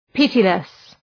Προφορά
{‘pıtılıs}